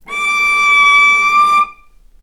healing-soundscapes/Sound Banks/HSS_OP_Pack/Strings/cello/ord/vc-C#6-ff.AIF at cc6ab30615e60d4e43e538d957f445ea33b7fdfc
vc-C#6-ff.AIF